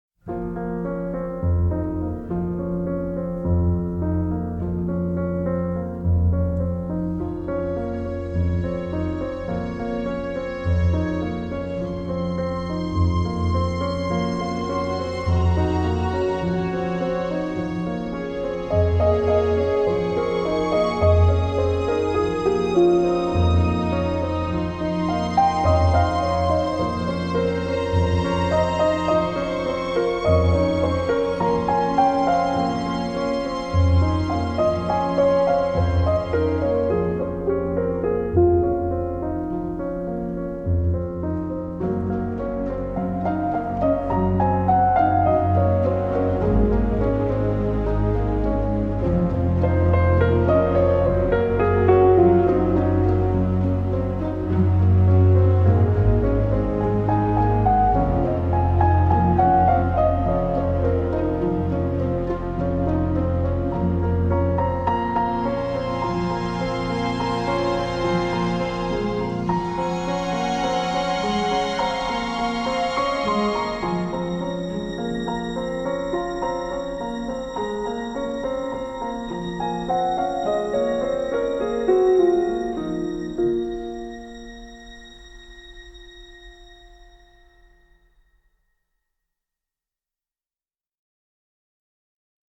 • الموسيقى أعلاه تعود للموسيقى التصويرية للفيلم .